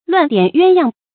亂點鴛鴦 注音： ㄌㄨㄢˋ ㄉㄧㄢˇ ㄧㄨㄢ ㄧㄤ 讀音讀法： 意思解釋： 鴛鴦：水鳥名；羽毛美麗；雌雄常在一起；文學作品中常用以比喻夫妻。